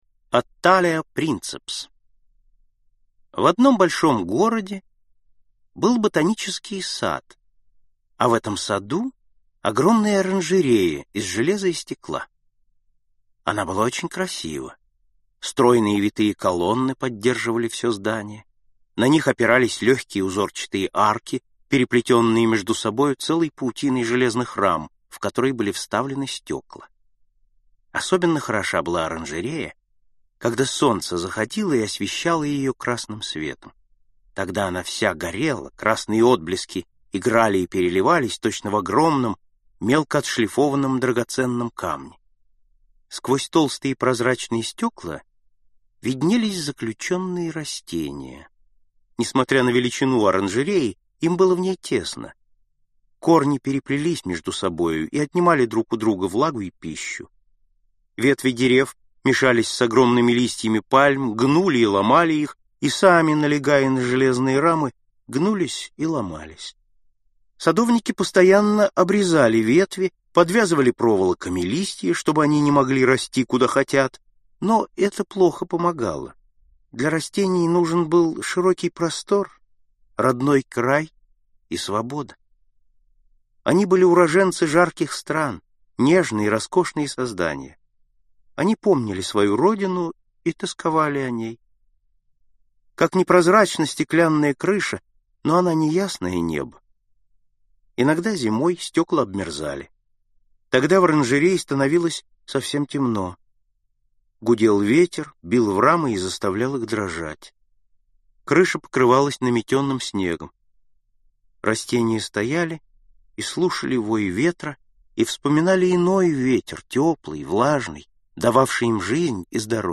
Аудиокнига Лягушка путешественница | Библиотека аудиокниг